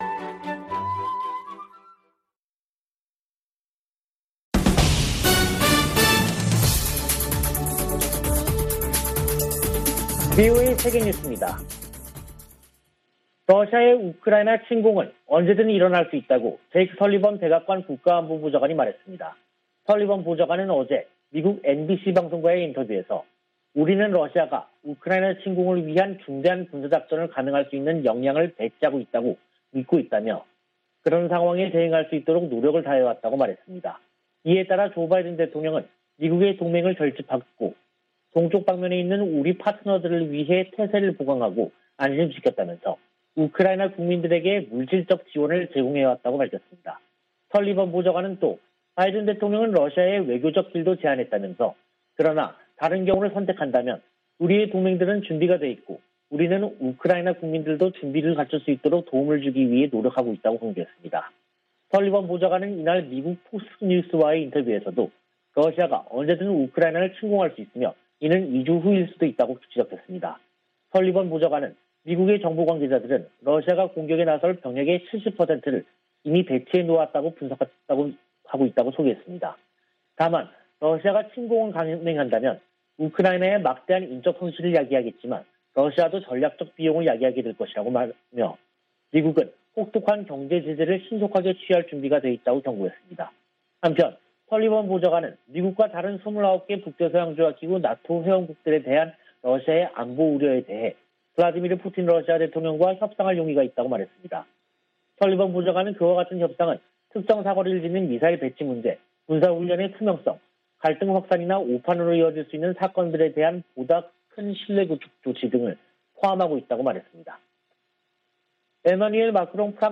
VOA 한국어 간판 뉴스 프로그램 '뉴스 투데이', 2022년 2월 7일 2부 방송입니다. 대니얼 크리튼브링크 미 국무부 동아시아 태평양 담당 차관보는 북한 위협 대응을 최우선 과제로 꼽았습니다. 미국의 핵·미사일 전문가들은 북한이 '공중 핵폭발' 역량을 확보한 것으로 분석했습니다. 유엔 안보리가 4일 북한의 잇따른 탄도미사일 발사 대응 관련 회의를 열었지만 규탄 성명 등을 위한 합의에 실패했습니다.